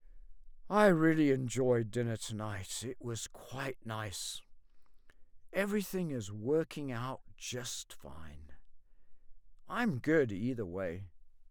p008_emo_contentment_sentences.wav